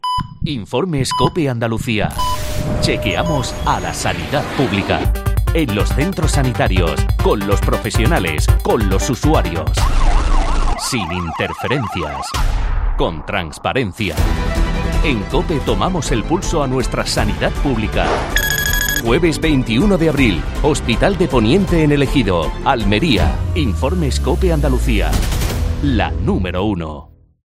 Desde las siete de la mañana hasta las ocho de la tarde estaremos en un centro sanitario, realizando toda nuestra programación en directo.
Este jueves 21 de abril, hemos realizado nuestra cuarta parada y nos hemos desplazado hasta el Hospital de Poniente, en El Ejido (Almería).